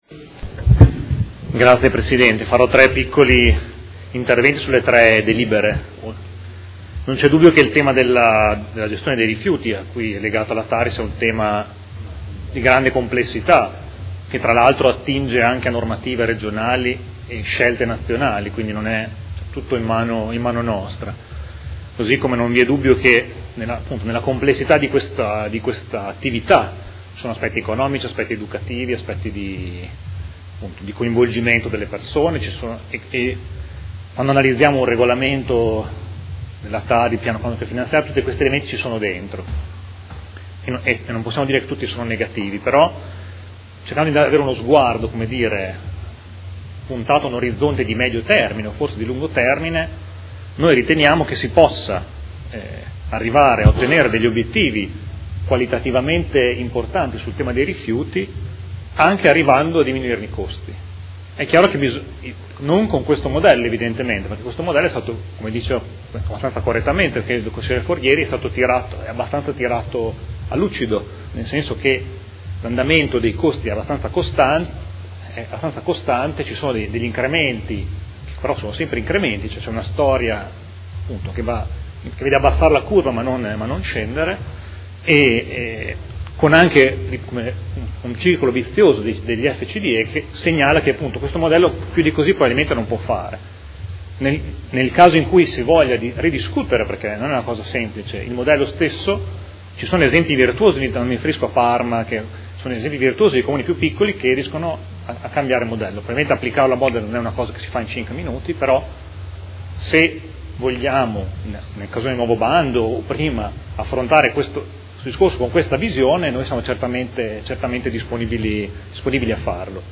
Seduta del 28 aprile 2016